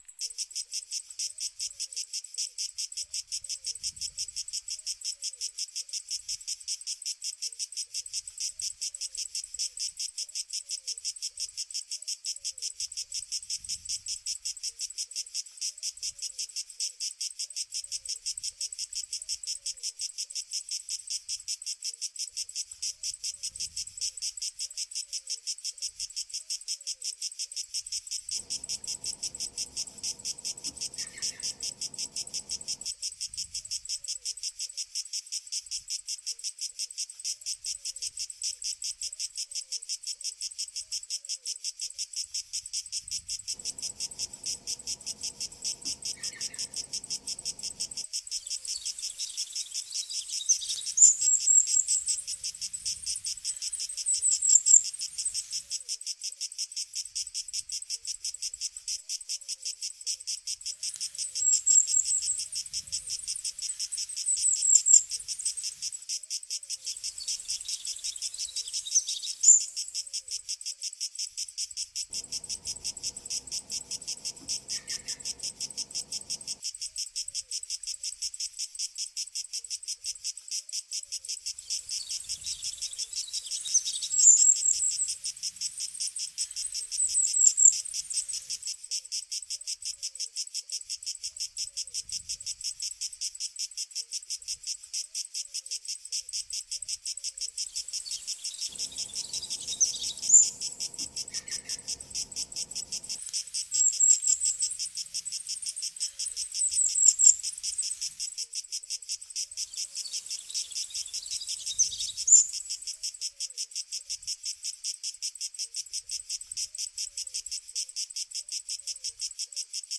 They're all relatively ecologically believable, meaning that you only hear animals that would be found in a same region and same general environment (e.g. a forest at night, a plain during summer...). They are build with an audio software and are not original recordings though.
Southern European summer", a soundscape originally made to reproduce an Italian vineyard.
south_euro_summer.ogg